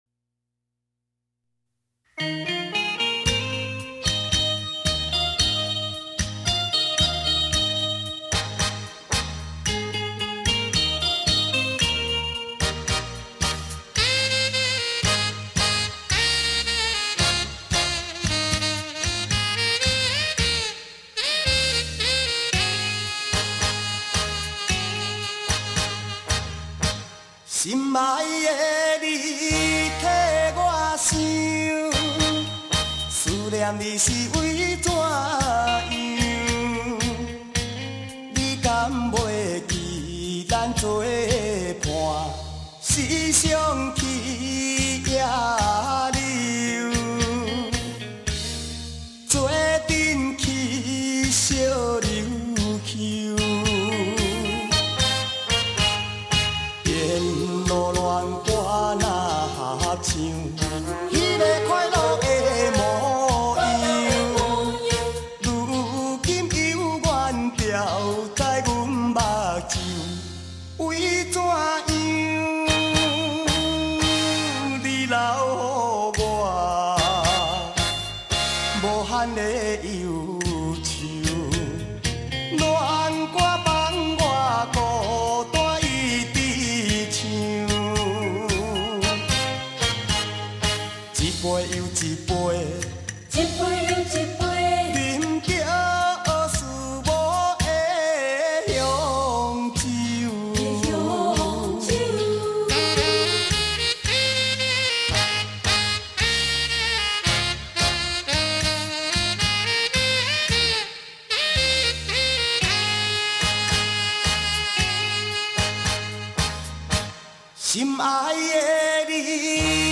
3CD 原录音 原歌声 原母带制作